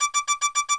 heatshieldsalarm.ogg